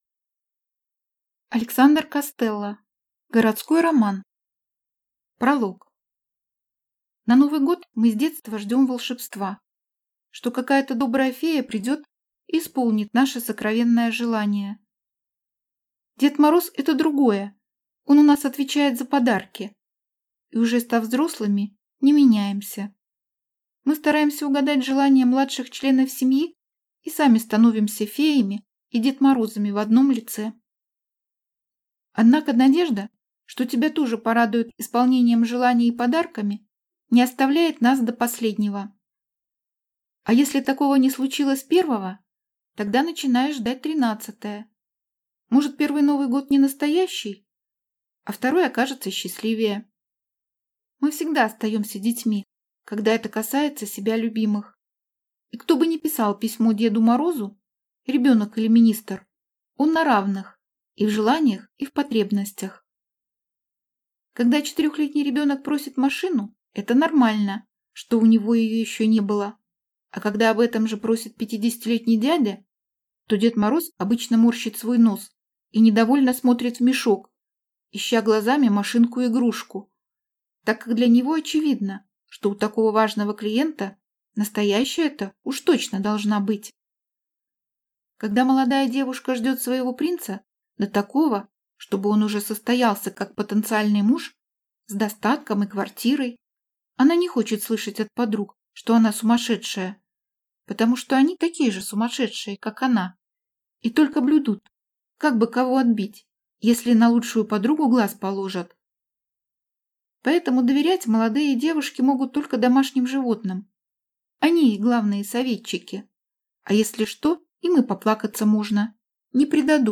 Аудиокнига Городской роман | Библиотека аудиокниг